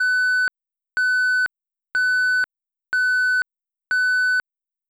beep.wav